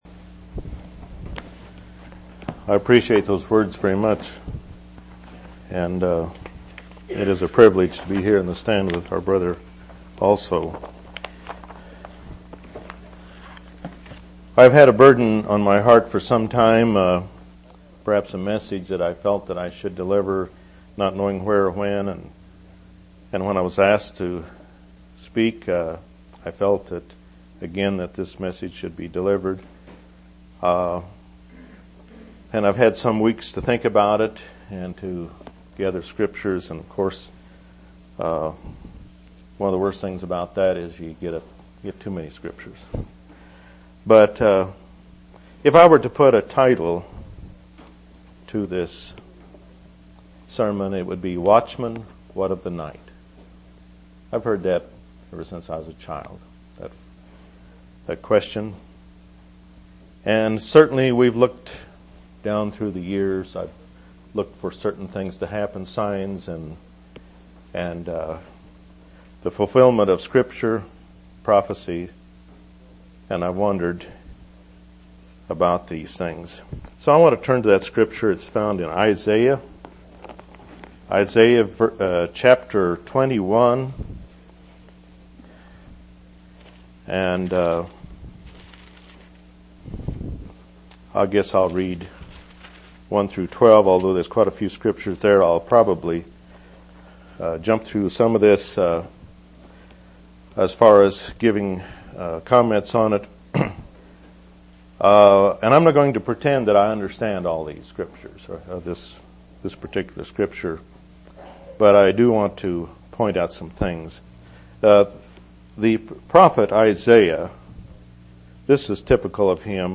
1/24/1999 Location: East Independence Local Event